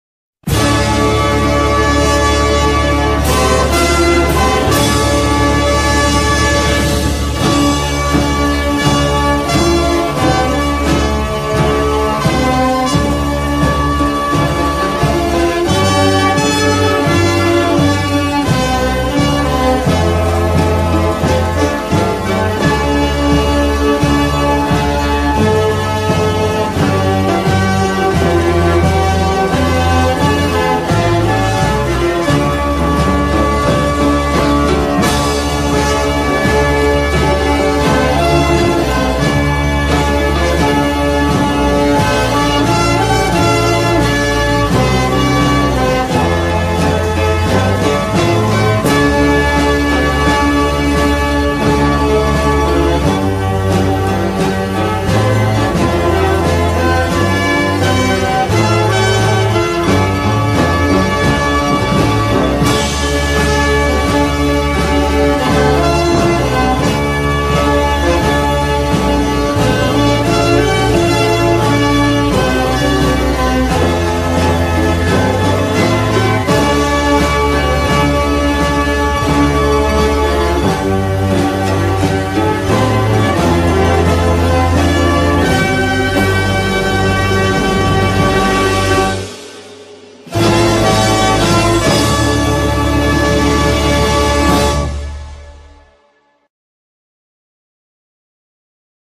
в инструментальном исполнении